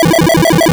retro_synth_beeps_fast_02.wav